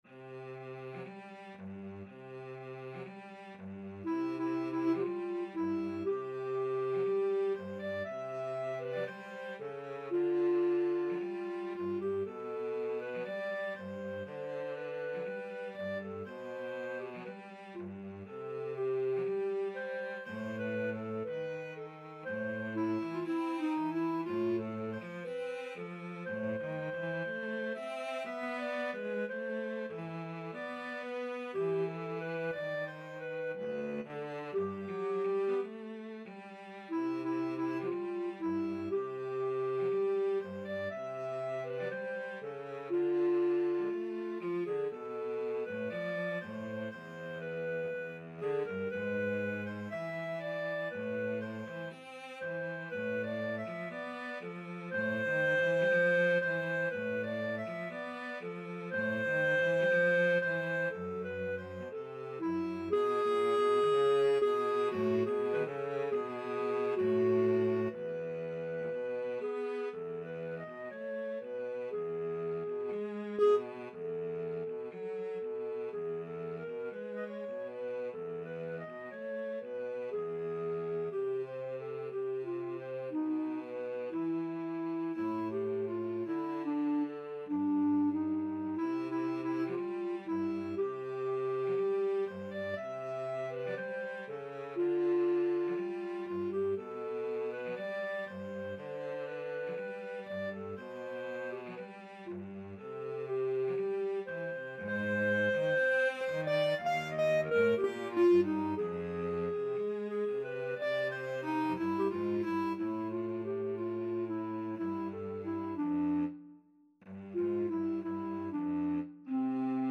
Free Sheet music for Clarinet-Cello Duet
2/4 (View more 2/4 Music)
C major (Sounding Pitch) D major (Clarinet in Bb) (View more C major Music for Clarinet-Cello Duet )
Classical (View more Classical Clarinet-Cello Duet Music)